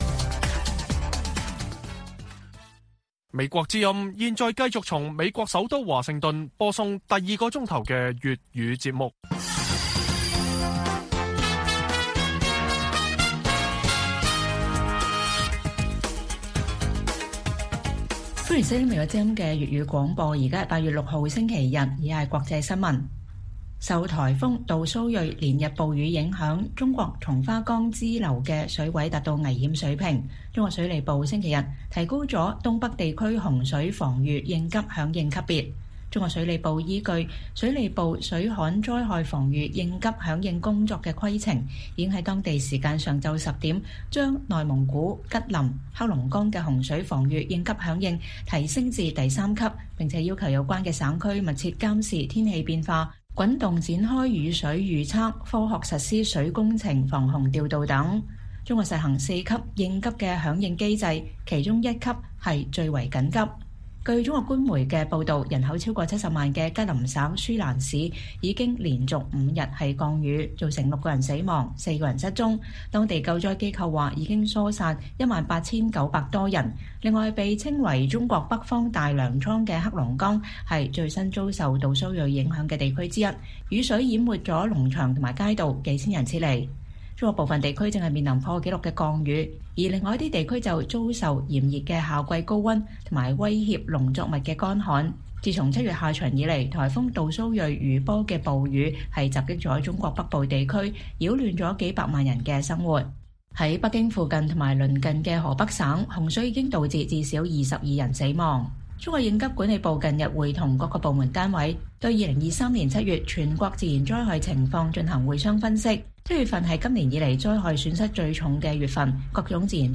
粵語新聞 晚上10-11點: 連日暴雨侵襲東北地區 中國提高了洪水防禦應急響應級別